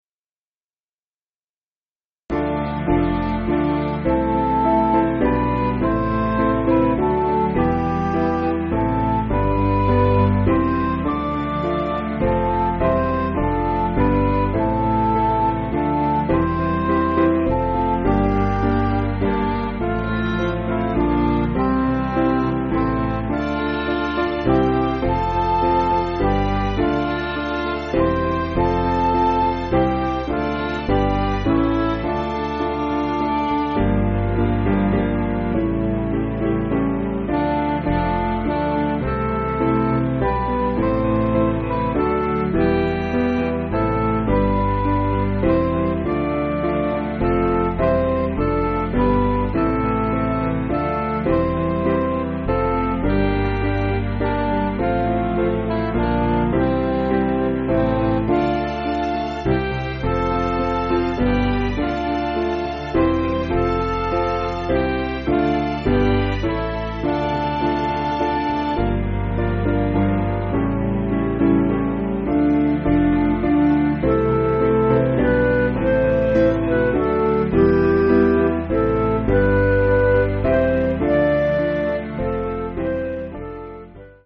Piano & Instrumental
(CM)   4/Dm